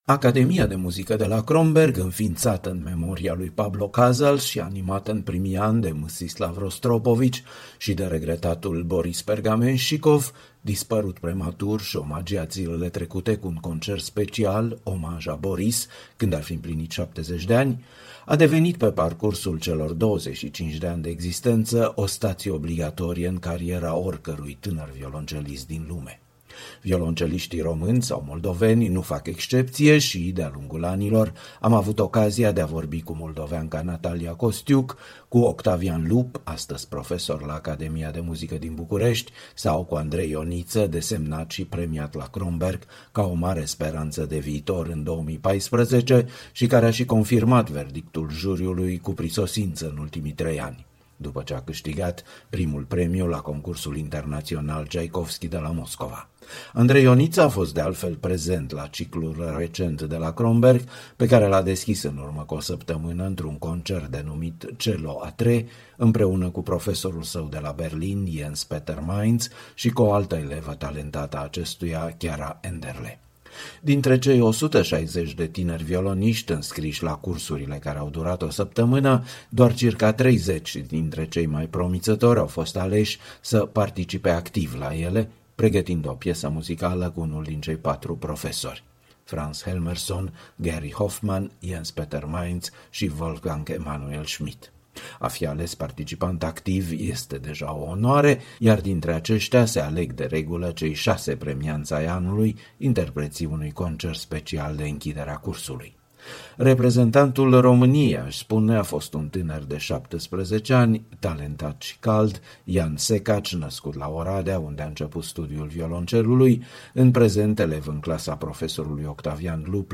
Un interviu cu tînărul violoncelist participant activ la cursurile de măiestrie de la Academia Kronberg (23-30 septembrie 2018).